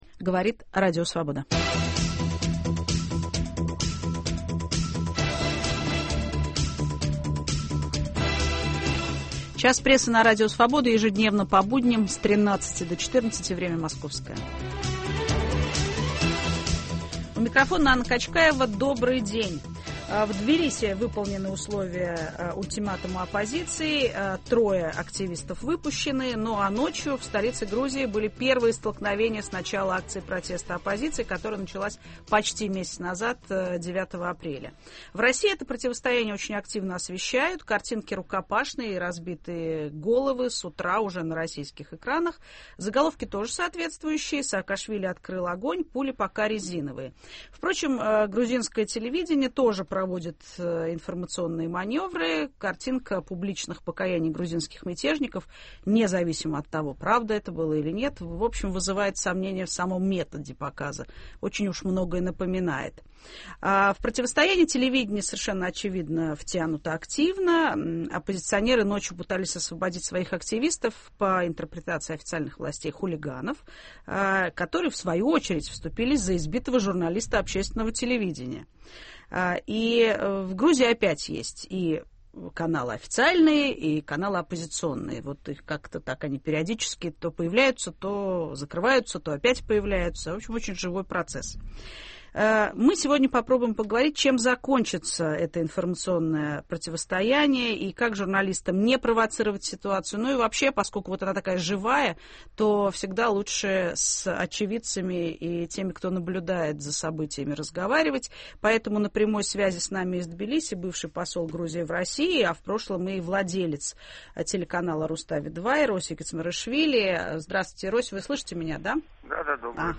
Информационные маневры Россия-Грузия. О тактике и перспективах информационного противостояния в прямом эфире дискутируют бывший посол Грузии в России Эроси Кицмаришвили и журналист Павел Шеремет.